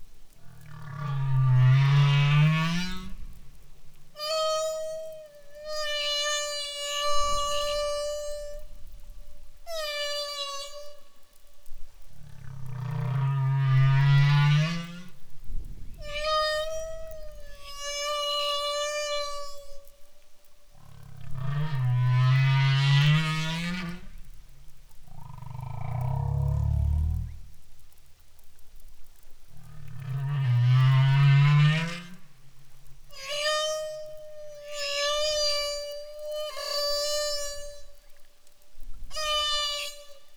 A key weapon in their courtship armoury appears to be the humpbacks’ propensity for elaborate songs.
complexity of the sounds could mean that male humpbacks need time to learn and refine their singing and other competitive tactics, suggest the scientists.